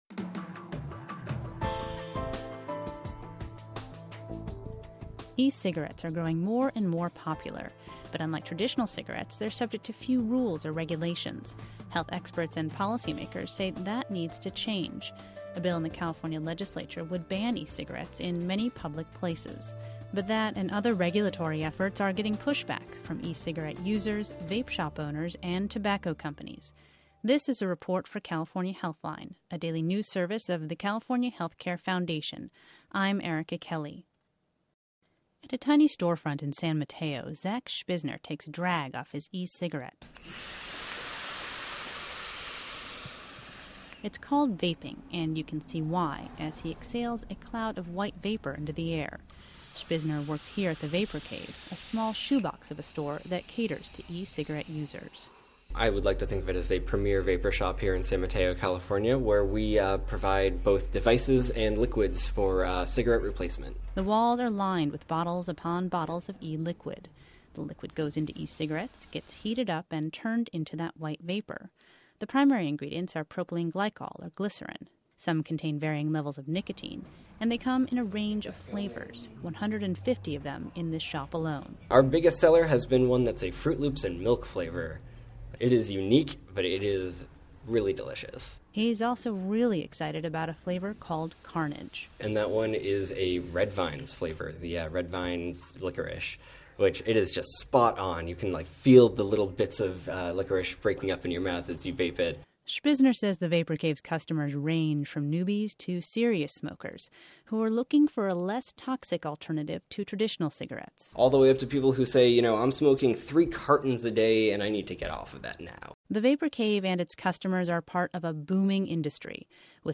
• State Sen. Mark Leno (D-San Francisco); and
Audio Report Insight Multimedia